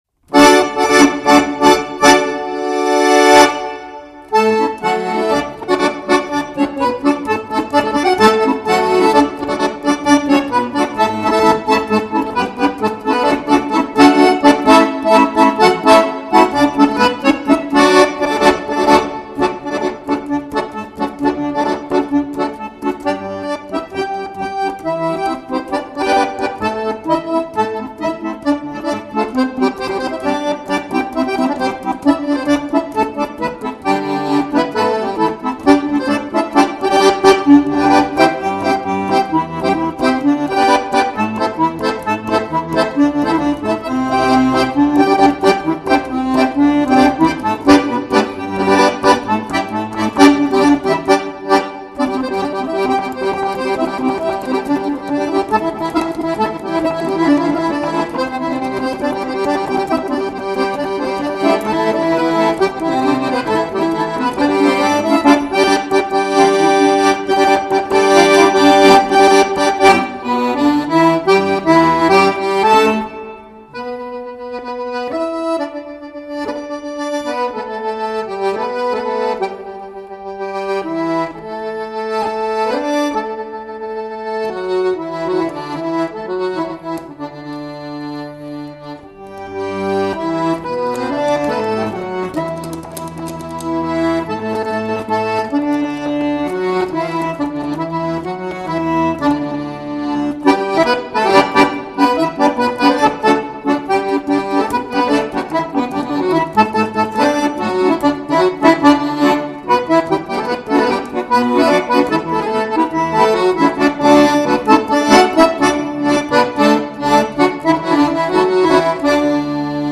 手风琴如飞鸟般掠过地平线的远方,音乐中呼唤出最隽永的时光,它记录着
本专辑汇聚了十四首经典欧亚手风琴作品，独奏重奏精彩纷呈。